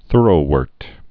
(thûrō-wûrt, -wôrt, thûrə-, thŭr-)